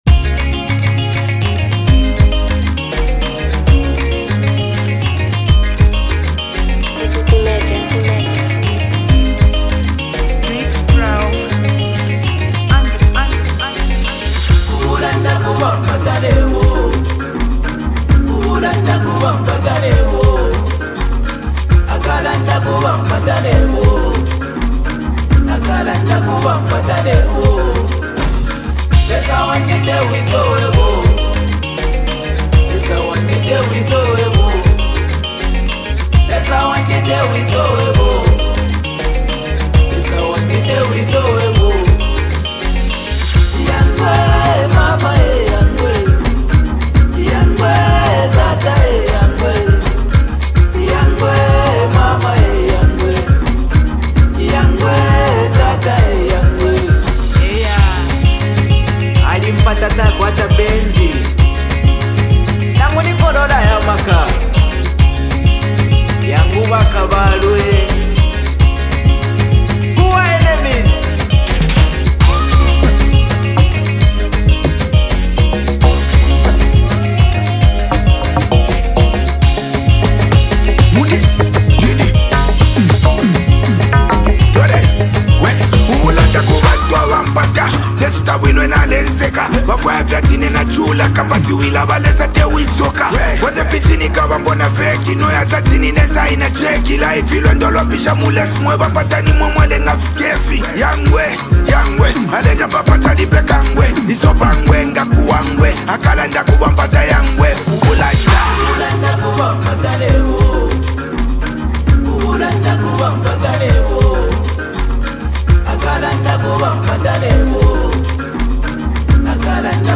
the iconic Zambian duo
energetic production